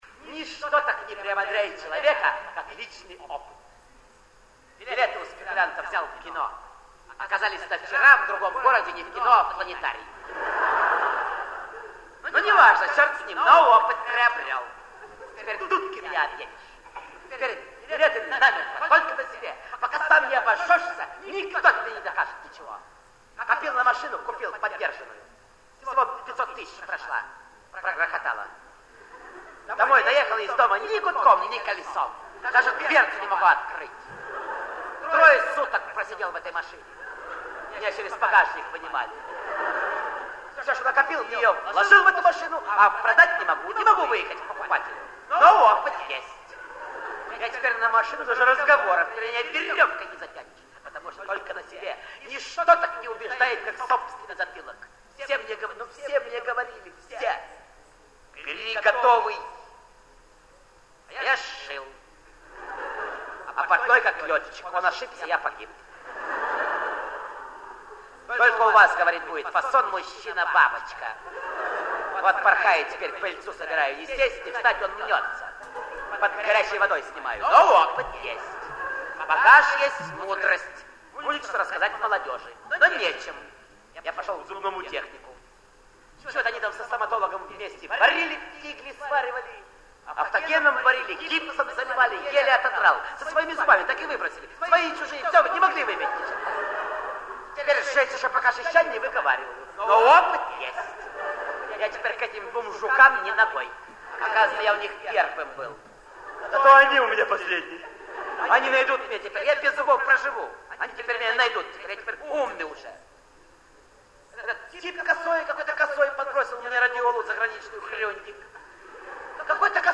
Продолжение редких записей миниатюр в исполнении Виктора Ильченко и Романа Карцева. 02 - В.Ильченко-Р.Карцев - Жду звонка